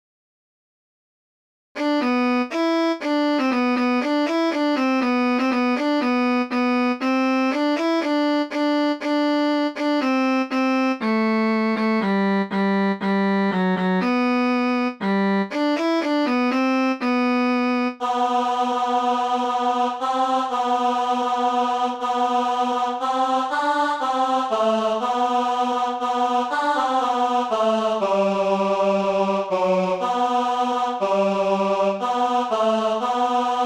tenor
adon_olam_tenor.mp3